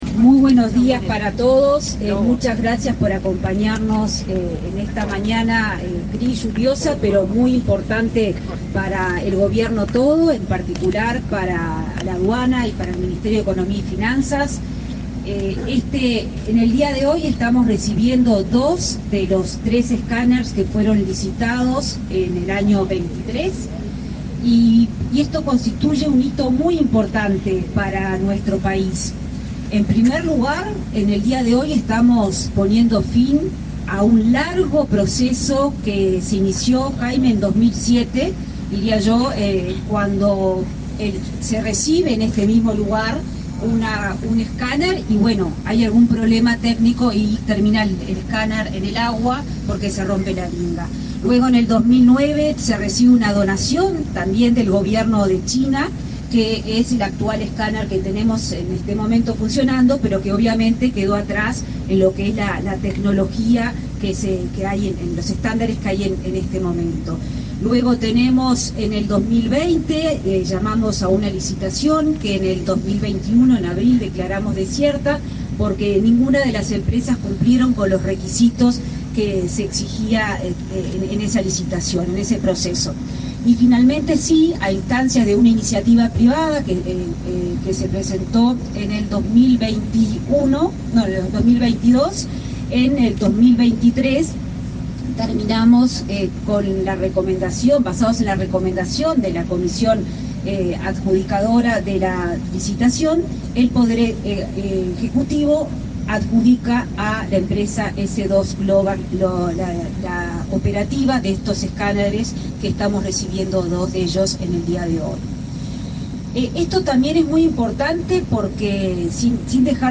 Declaraciones de la ministra de Economía y Finanzas, Azucena Arbeleche
Declaraciones de la ministra de Economía y Finanzas, Azucena Arbeleche 21/06/2024 Compartir Facebook X Copiar enlace WhatsApp LinkedIn Tras la llegada de nuevos escáneres para la Dirección Nacional de Aduanas, la ministra de Economía y Finanzas, Azucena Arbeleche, realizó declaraciones a la prensa.